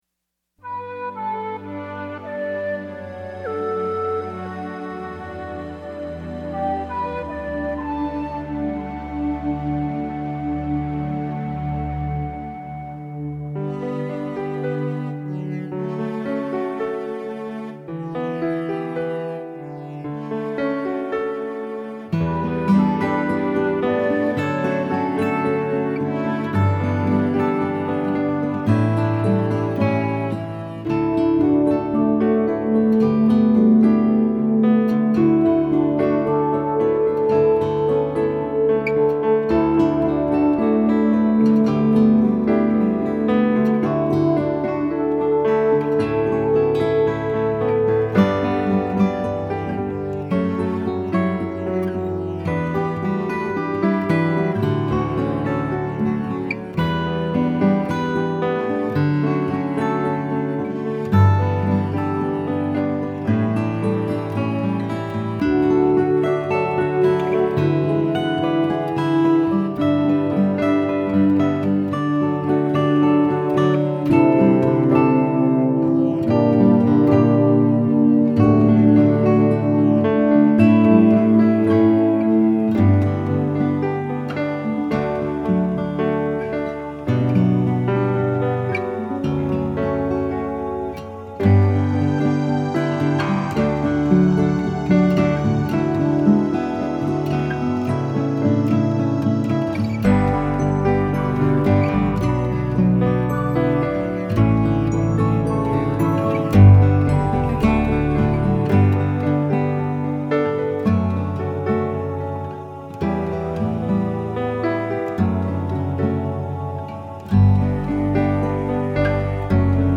I have the guitar louder so I can hear it clearly to check my editing.
every-season-guitar-mix.mp3